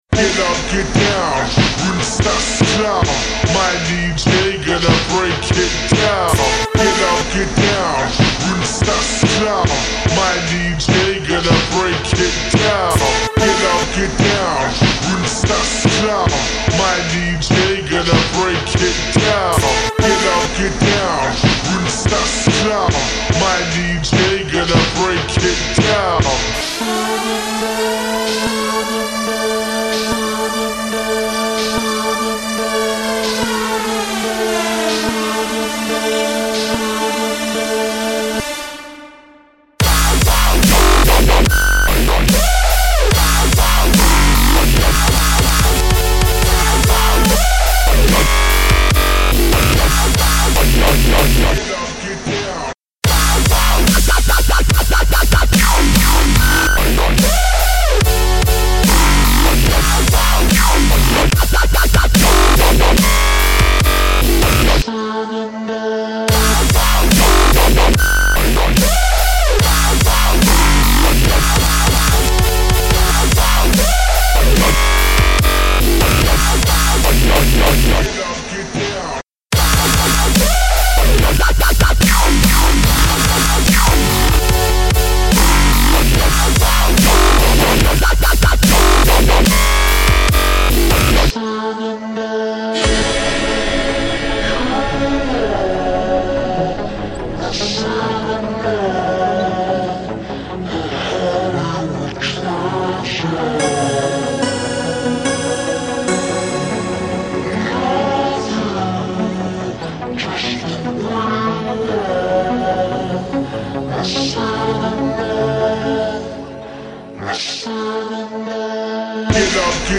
DUB STEP--> [6]